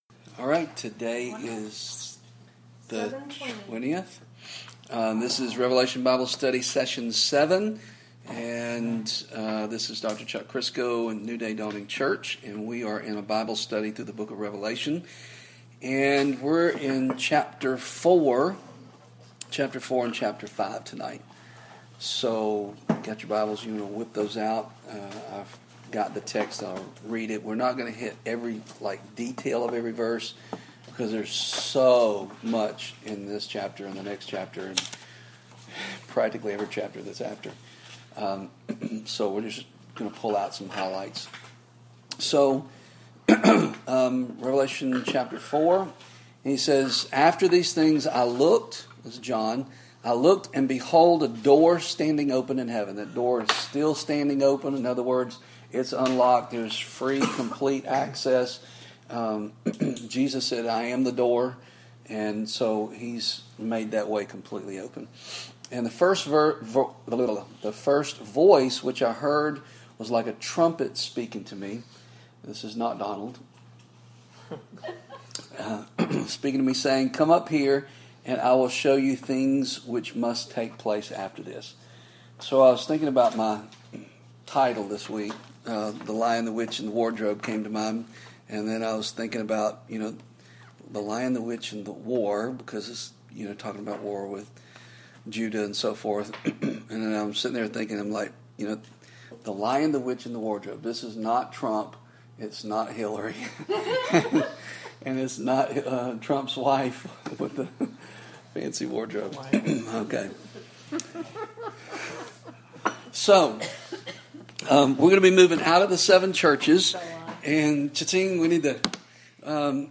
Revelation+Bible+Study,+Session+7,+The+Lion,+the+Witch+and+the+War.mp3